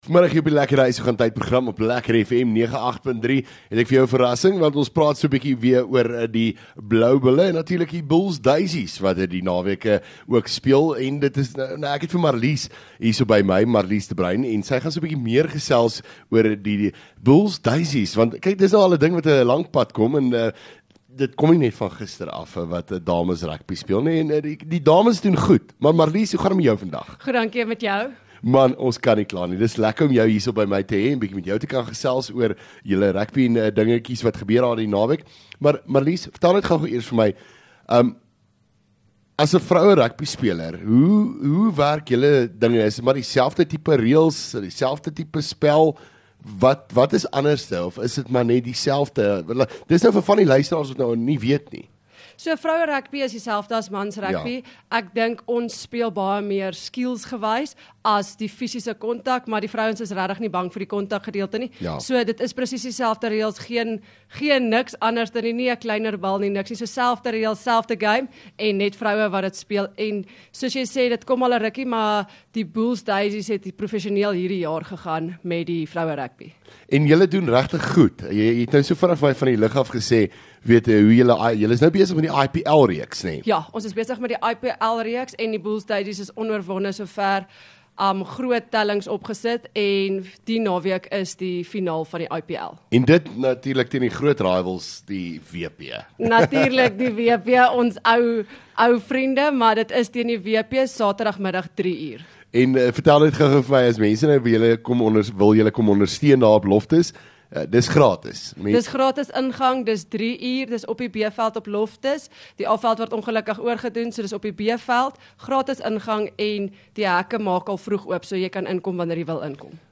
LEKKER FM | Onderhoude 24 Aug Bulls Daysies